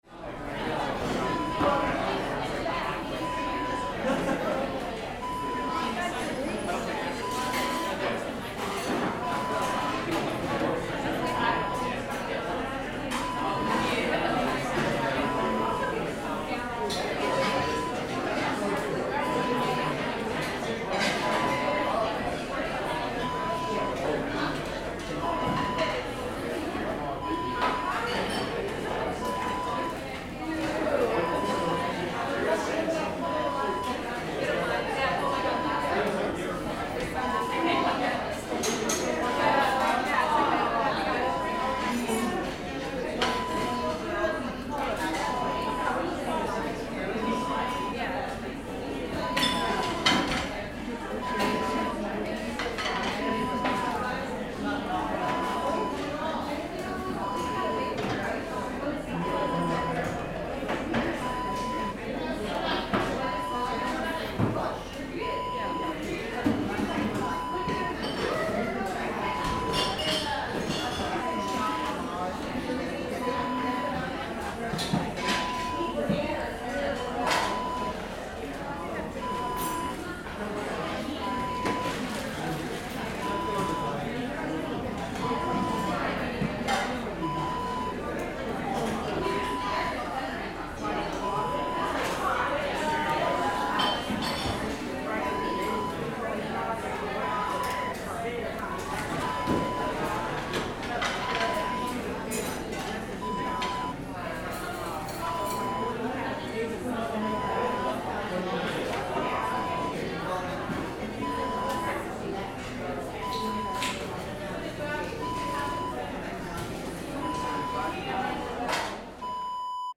Restaurant Background Ambience Wav Sound Effect #4
Description: Restaurant background ambience. Sounds of dishes, glassware and people talking.
Properties: 48.000 kHz 24-bit Stereo
A beep sound is embedded in the audio preview file but it is not present in the high resolution downloadable wav file.
Keywords: restaurant, ambience, crowd, people, talk, talking, walla, dining, eating
restaurant-ambience-preview-04.mp3